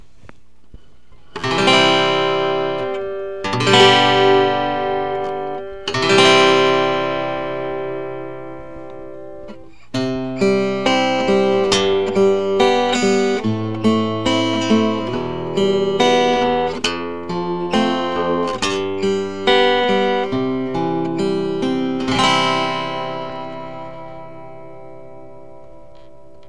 386煙AMP　アコギピックアップ研究編　貼り位置による差
貼り付け、PCで録音